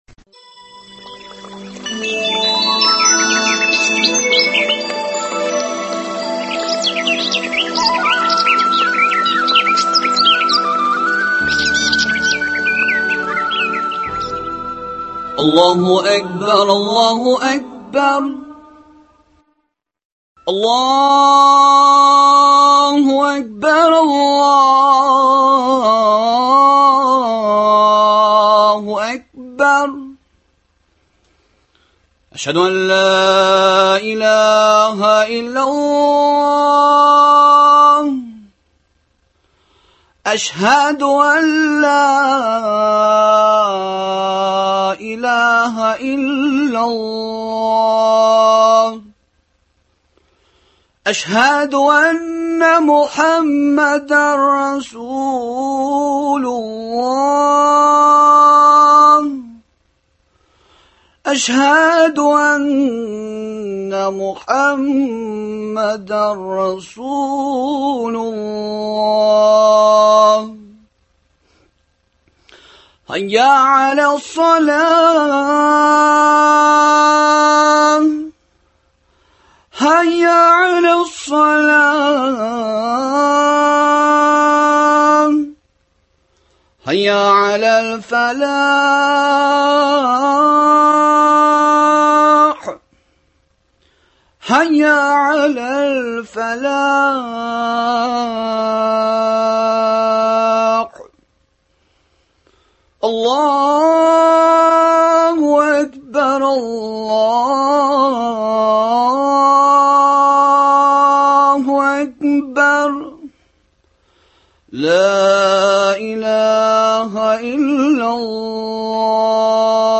кешелекнең бөтен дини конфессияләргә хас булган мәңгелек кыйммәтләре турында әңгәмә.